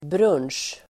Ladda ner uttalet
Uttal: [brun:sj]